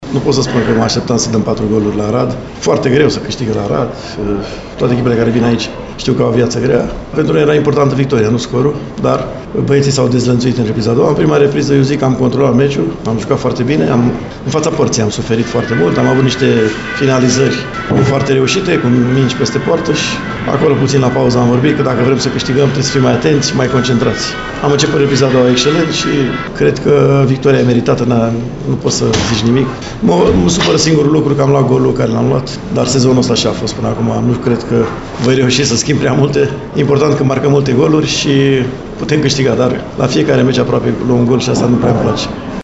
”Principalul” Dan Petrescu s-a arătat el însuși surprins de cât de mult a putut echipa lui să marcheze, la Arad: